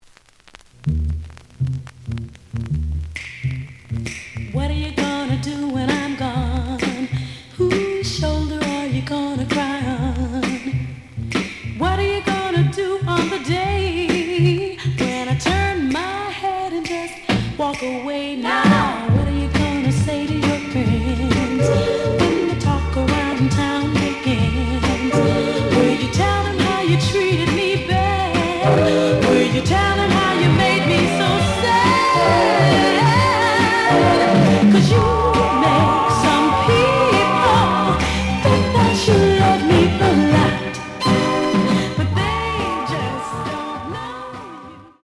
The audio sample is recorded from the actual item.
●Genre: Soul, 60's Soul
Slight noise on A side.